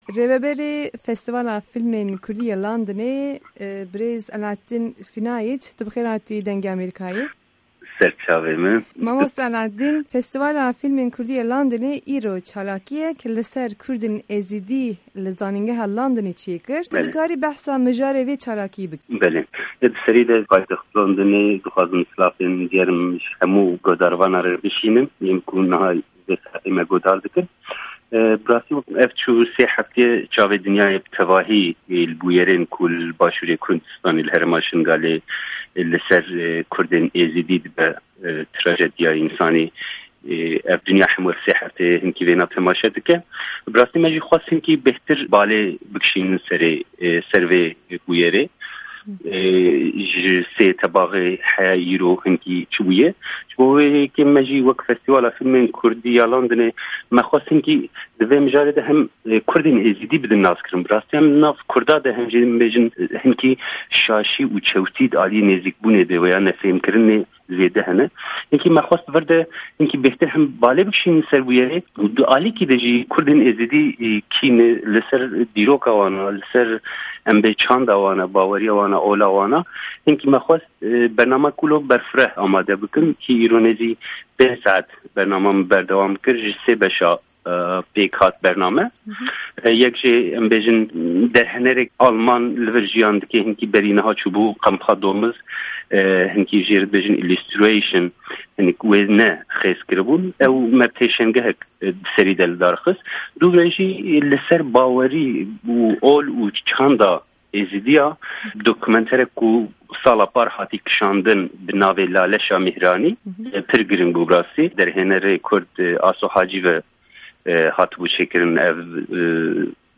hevpeyv'n